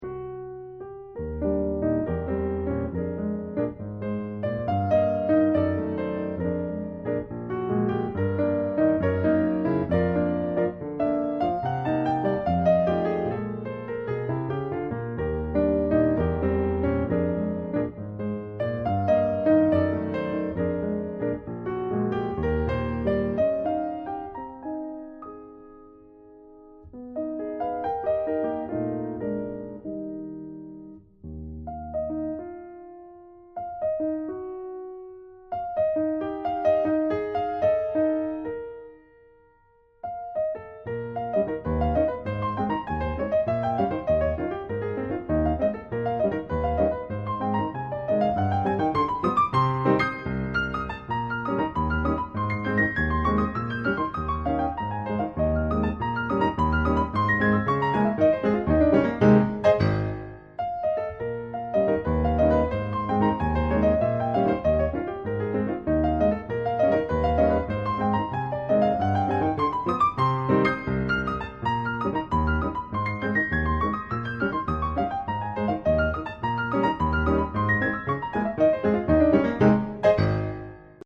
巴西作曲家Nazareth / 鋼琴作品